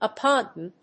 音節op・pugn 発音記号・読み方
/əpjúːn(米国英語)/